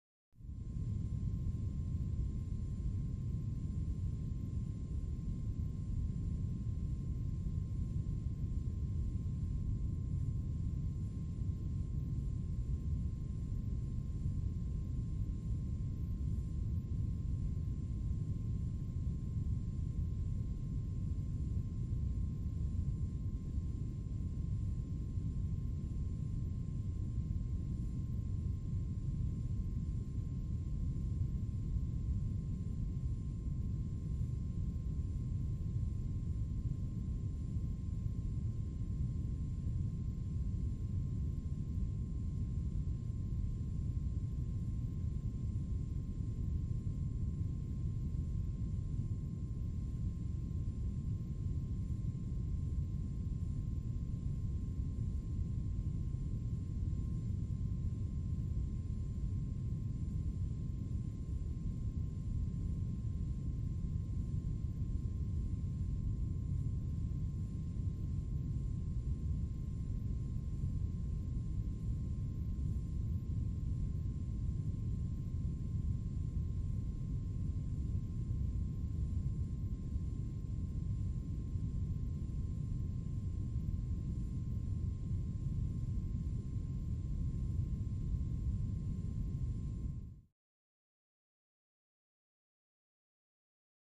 Room Tone - Good Hum